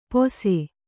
♪ pose